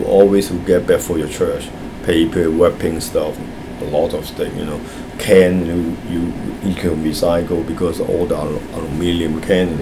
S1 = Taiwanese female S2 = Hong Kong male Context: S2 is talking about recycling in the United States.
S2 pronounces the initial [r] as [w] . The vowel in the first syllable is also not very open, so it sounds like [e] rather than [æ] .